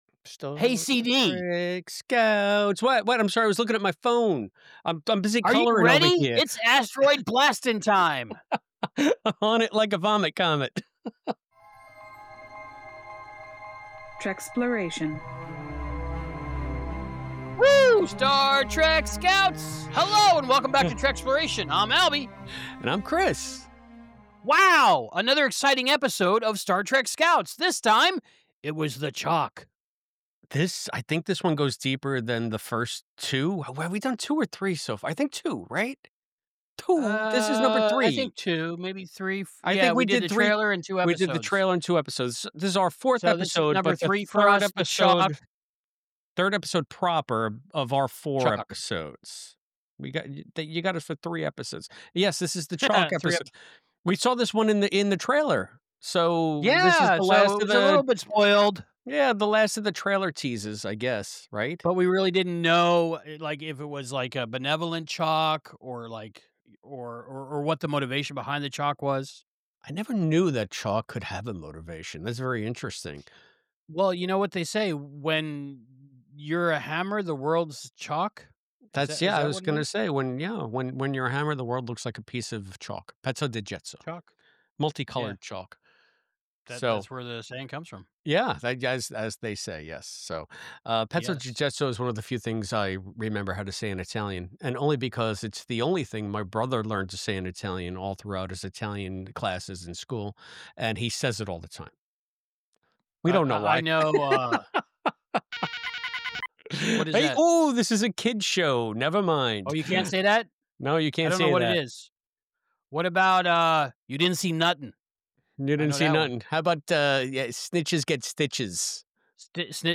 Join them for a thought-provoking discussion filled with laughter, speculation, and a touch of nostalgia.